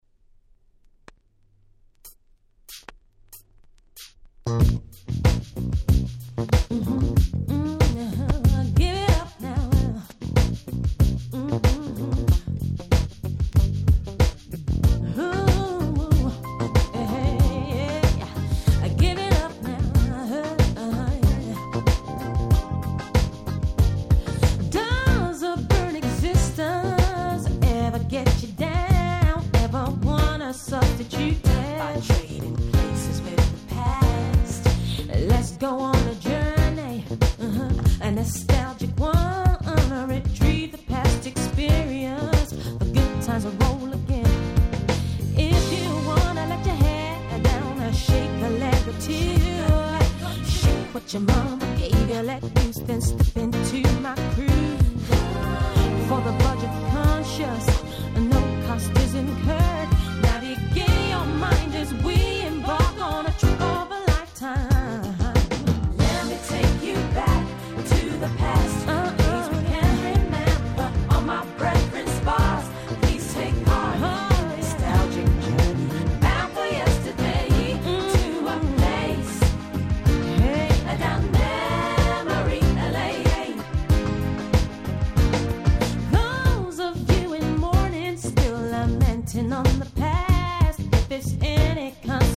99' Nice UK Soul LP !!
頭から尻尾の先までNiceなUK Soulがてんこ盛り！